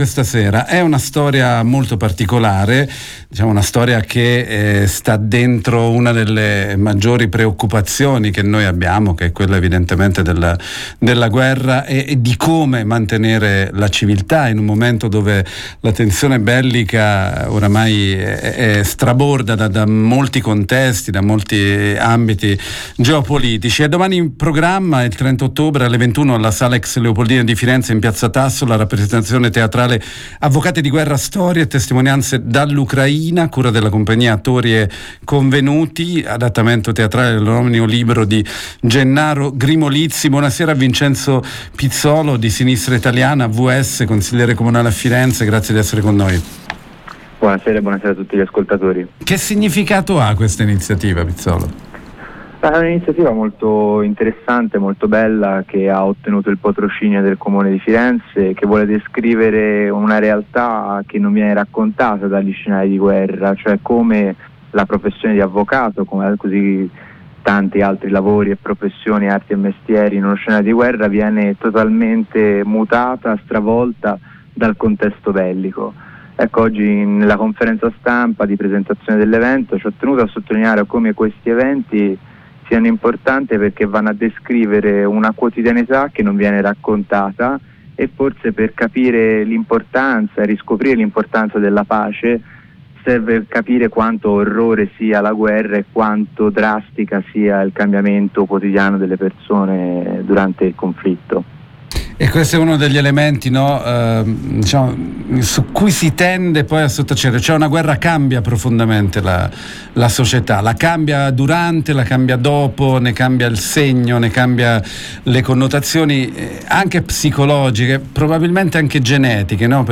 Si tratta dell’adattamento teatrale dell’omonimo libro di Gennaro Grimolizzi. Ne abbiamo parlato con Vincenzo Pizzolo, consigliere comunale Sinistra Italiana-AVS